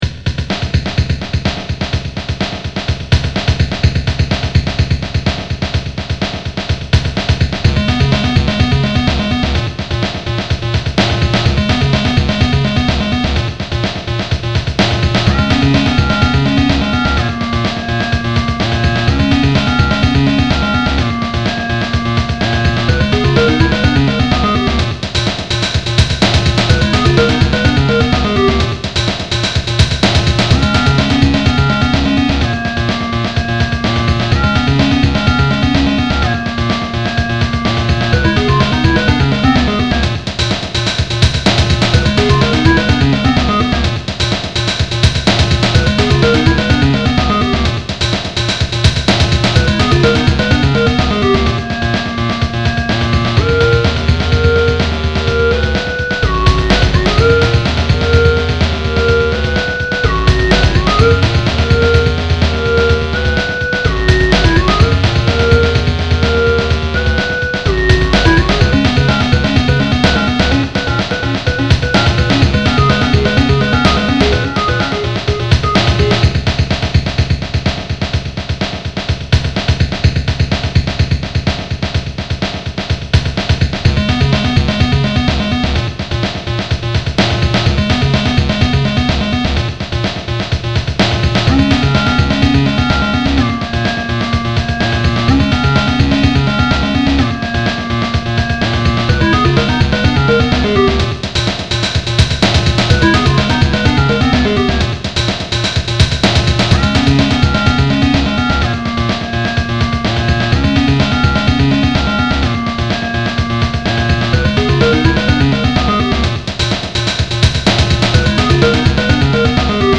Again, even though you never asked for it, here it is - another Microbe extravaganza produced entirely on the Palm (well, except for the fade-out at the end, which I processed in Audacity on my Mac):
Wong (hi-bandwidth / stereo) - will pop up in a new window